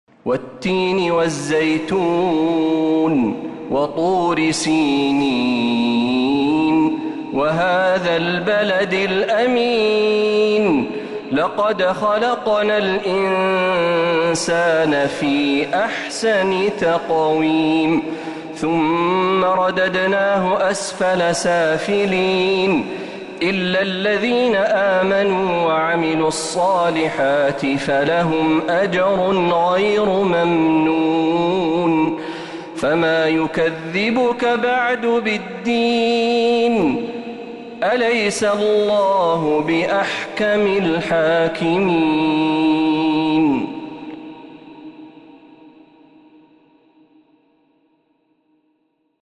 سورة التين كاملة من الحرم النبوي